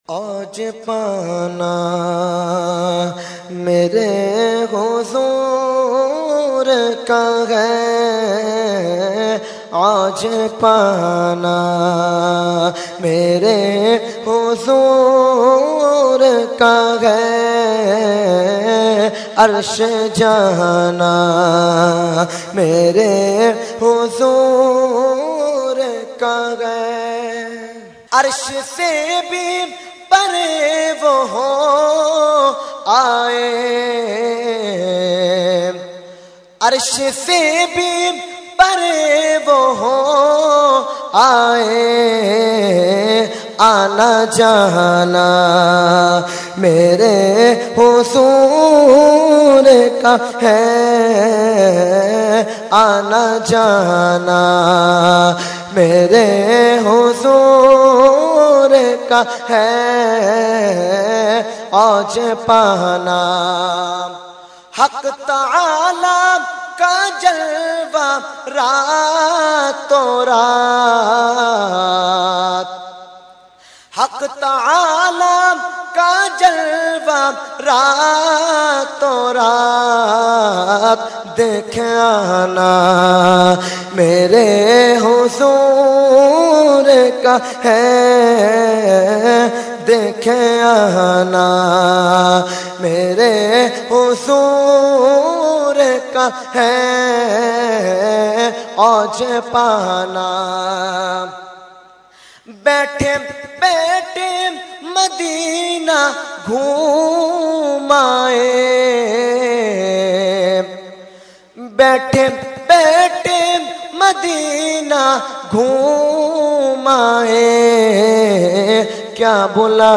Category : Naat | Language : UrduEvent : Shab e Meraaj 2015